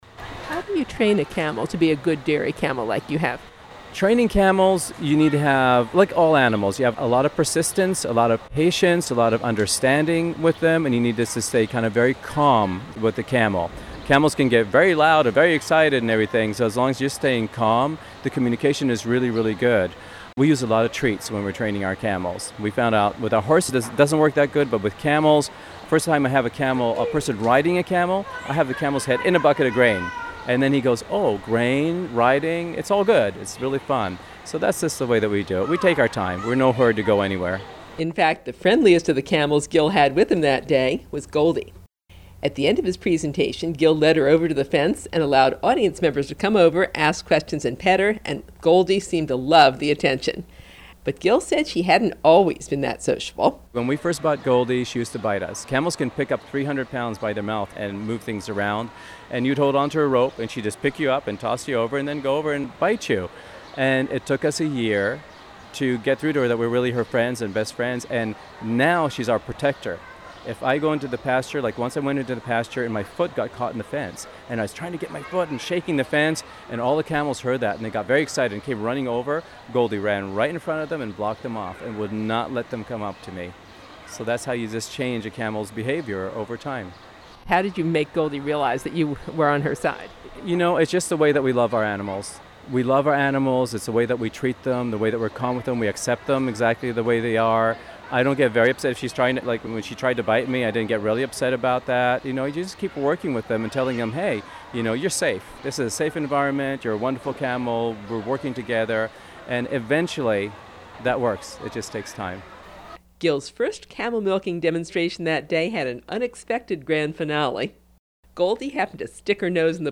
camelmilking_2.mp3